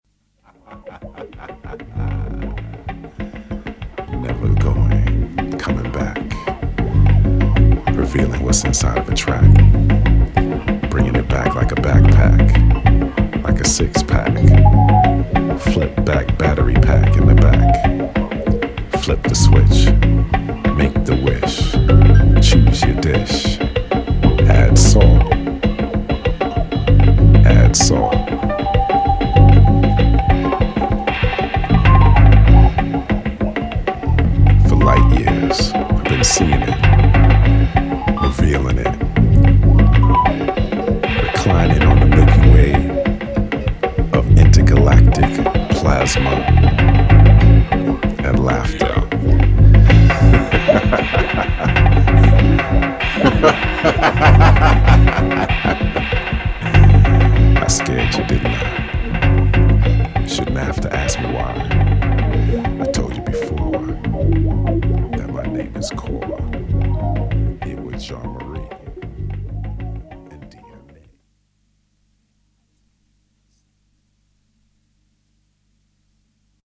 een funky jazzsfeer